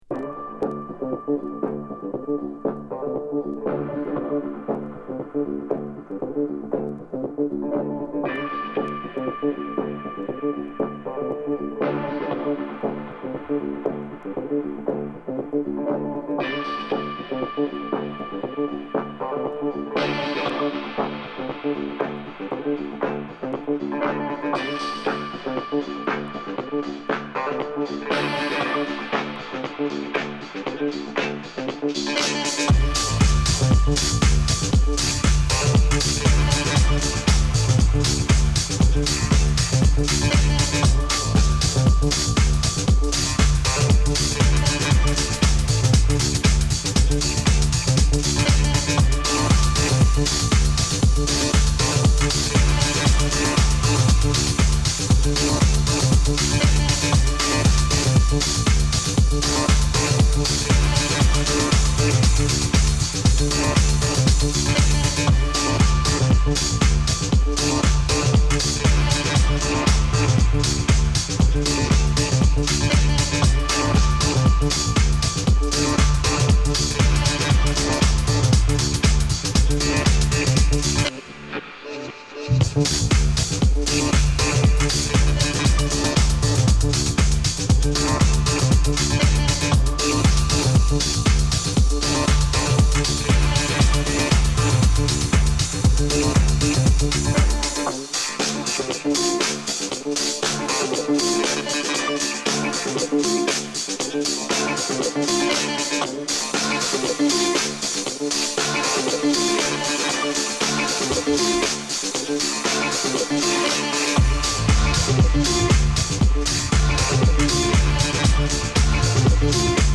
＊試聴はA→B1→B2です。